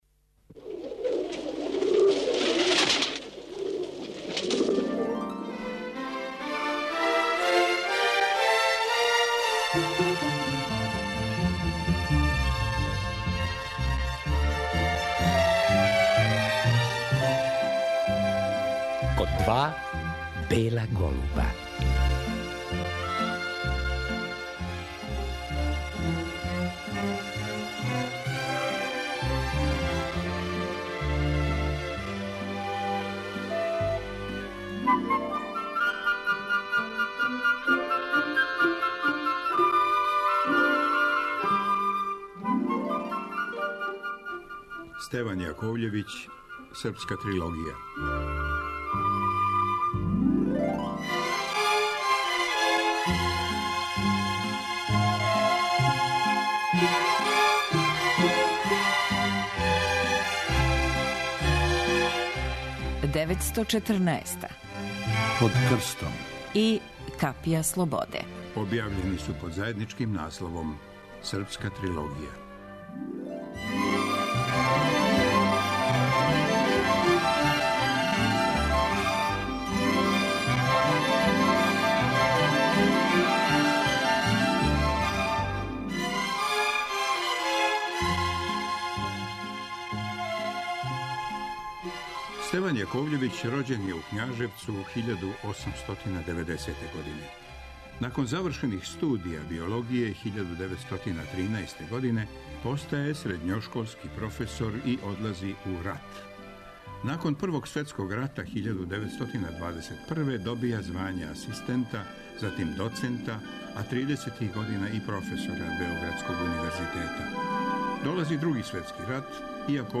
О настанку ове књиге говоримо у вечерашњој емисији. Чућемо и снимак сећања Стевана Јаковљевића из 1961. године.